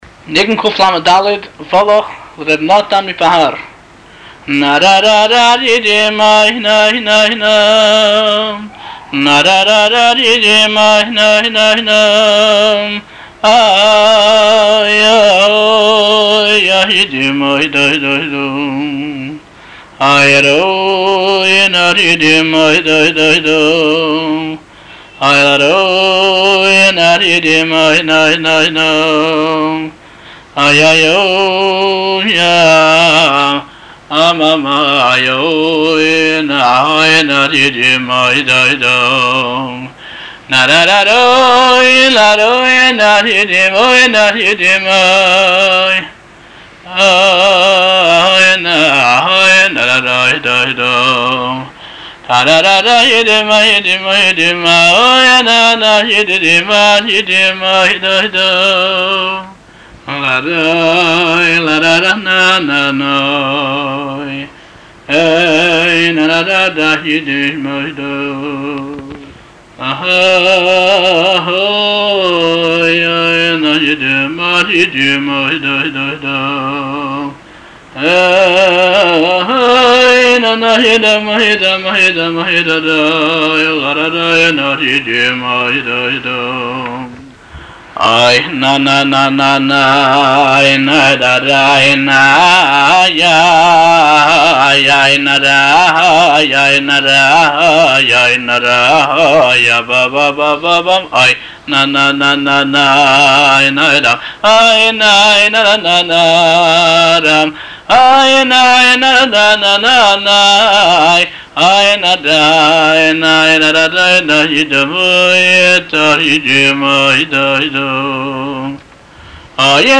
לשמיעת הניגון מאת הבעל-מנגן